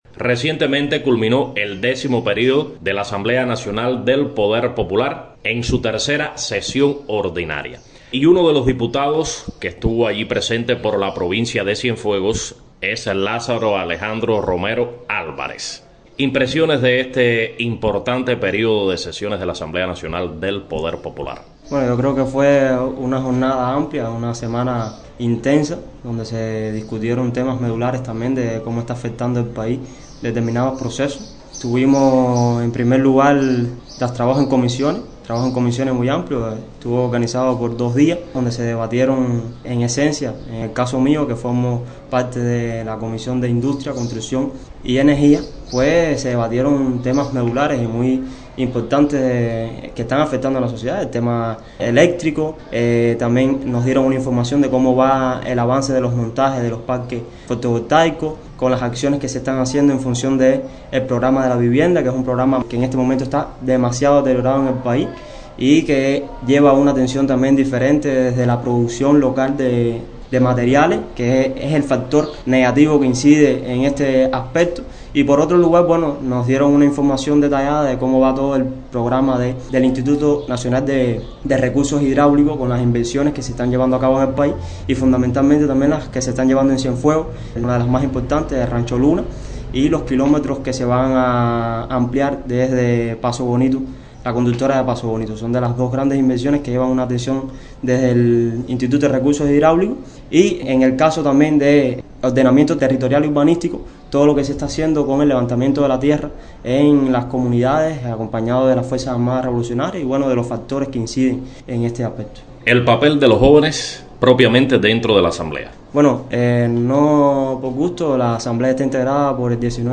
Lázaro Alejandro Romero Álvarez, diputado a la Asamblea Nacional del Poder Popular por el municipio de Cienfuegos, recientemente participó en el tercer período de la décima legislatura del máximo órgano gubernamental, a propósito de los temas abordados en el cónclave, dialogó con la prensa.